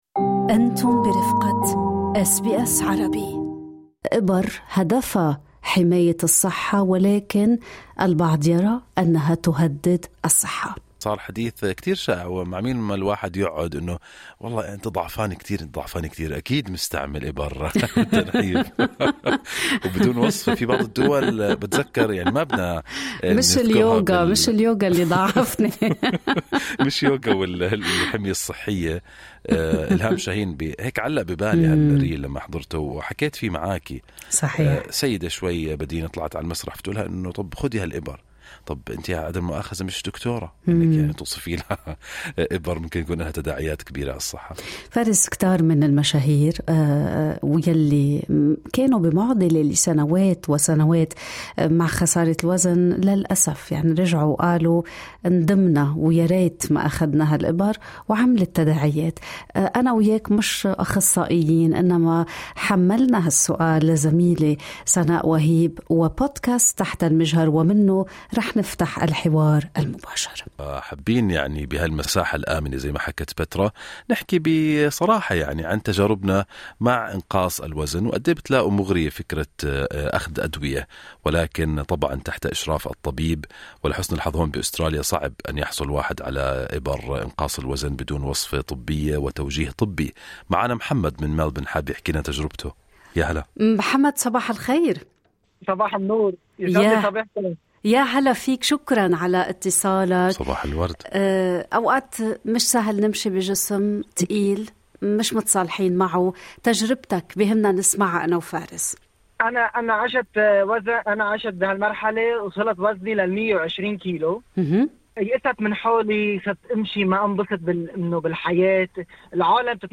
اليكم أراء المستمعين عبر برنامج "صباح الخير استراليا"؟